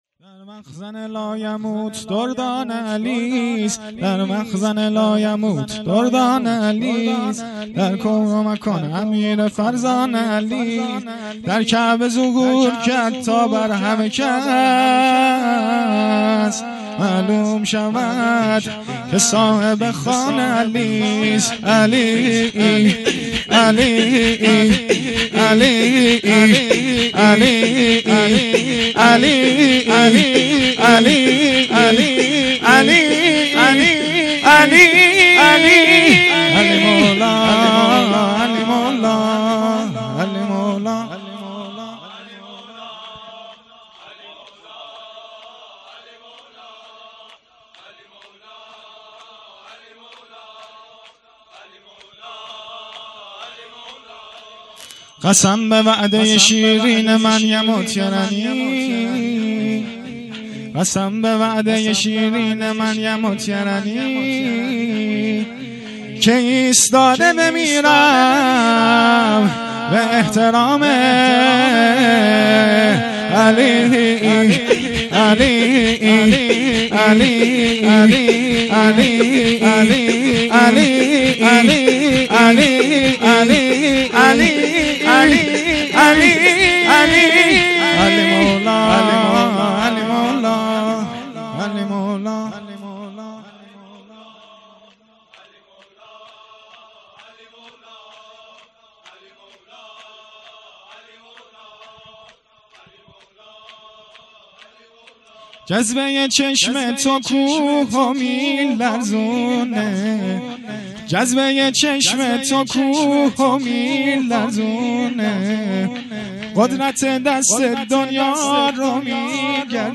مراسم شهادت حضرت فاطمه زهرا سلام الله علیها آبان ۱۴۰۳